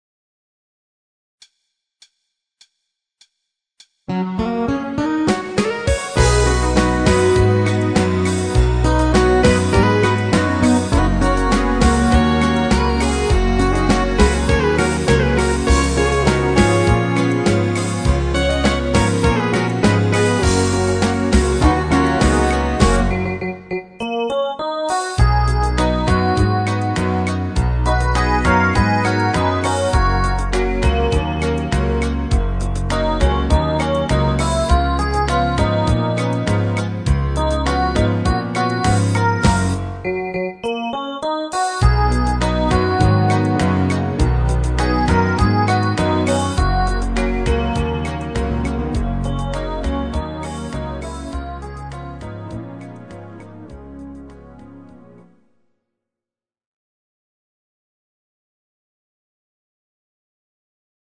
Genre(s): Oldies  Country  |  Rhythmus-Style: Ballade